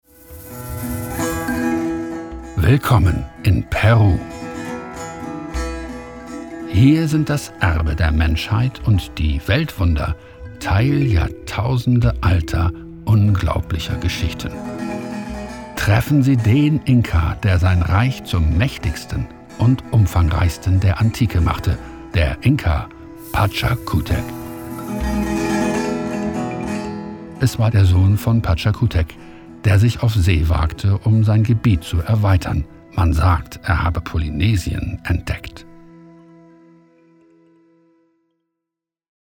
Deutscher und englischer Sprecher mit charismatischer, tiefer Stimme für Werbung, Film, Fernsehen, Synchronisation, Radio, Corporate Film, Audio Touren und E-Learning.
Sprechprobe: Werbung (Muttersprache):